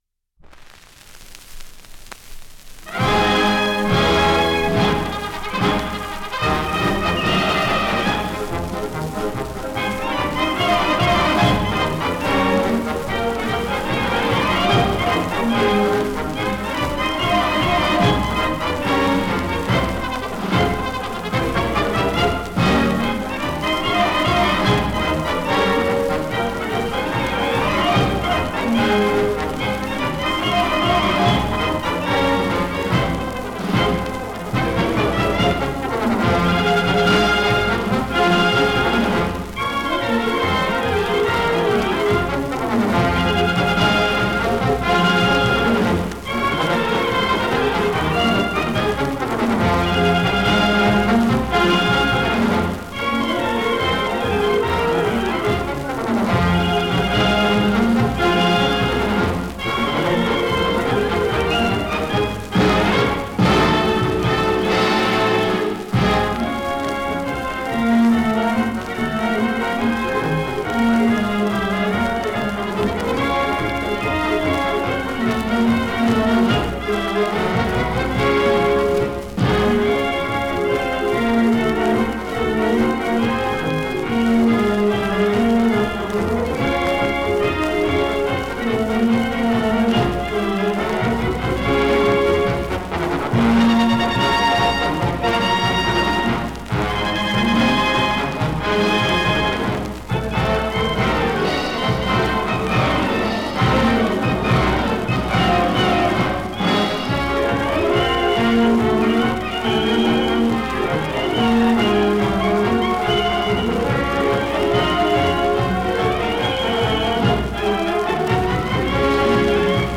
Marcia del Circo Barnum and Bailey
marcia.mp3